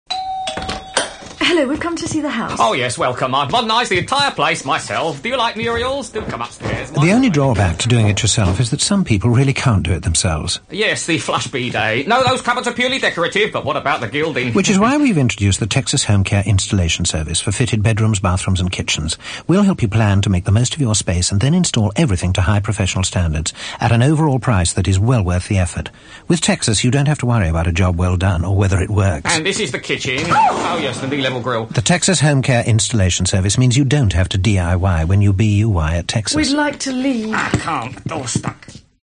A shopping list of the usual suspects and services, graced by some wonderful performances from Geoffrey Palmer and others, presented as individual comedy cameos.